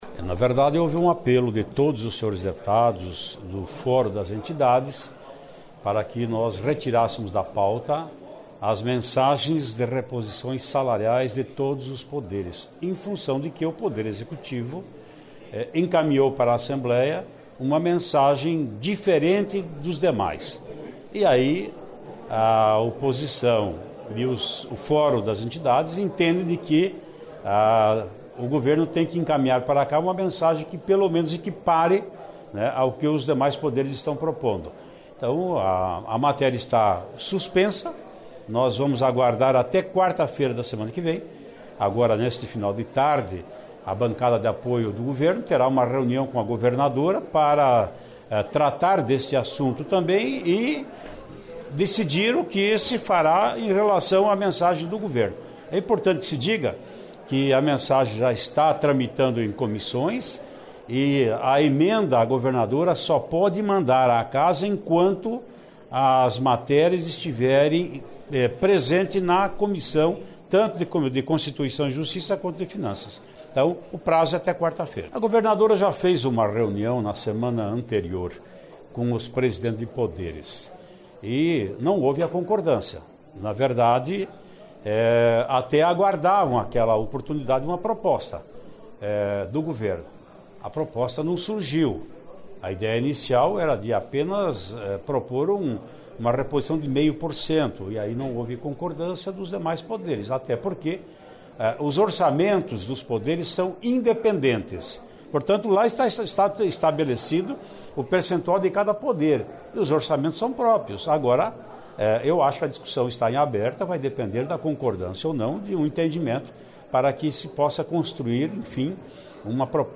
Ouça entrevista com o presidente da Assembleia Legislativa, deputado Ademar Traiano (PSDB), onde ele fala que houve entendimento entre Base do Governo e oposição para a retirada e como as propostas devem tramitar na Casa.
(Sonora)